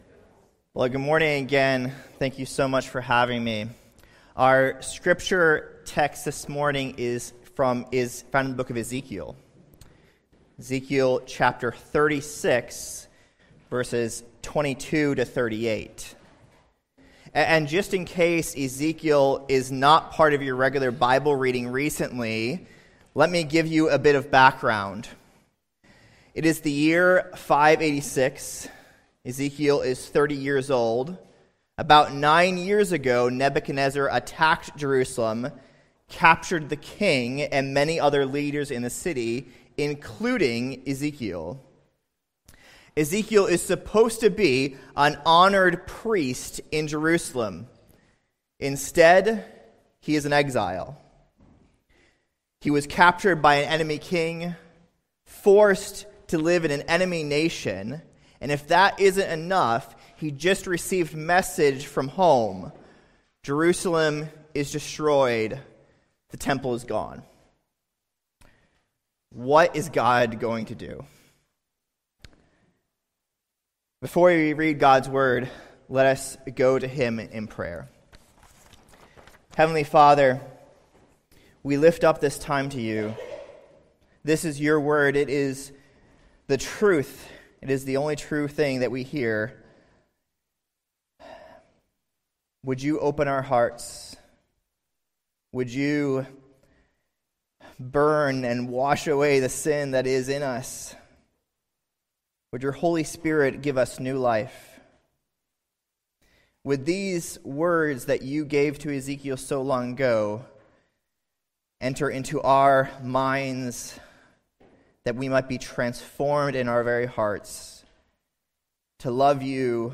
Guest Preachers